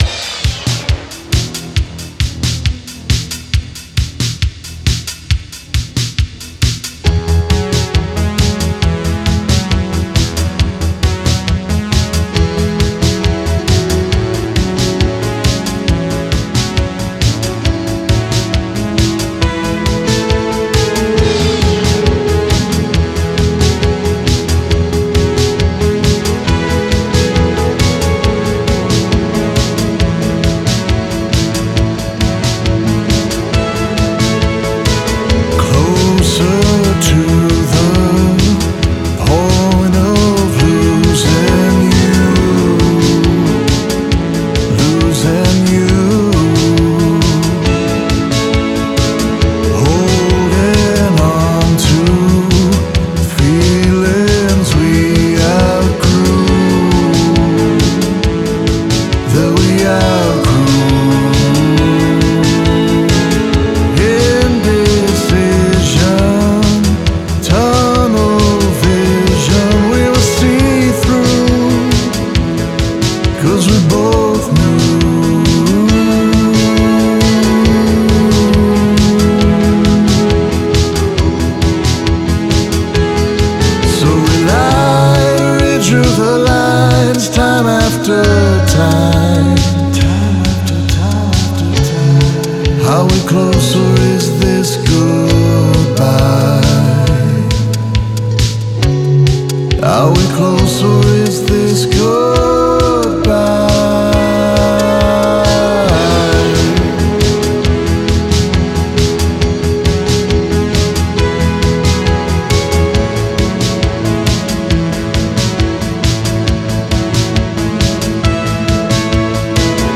INTRO - 16 BARS
OUTRO/INSTRUMENTAL - 40 BARS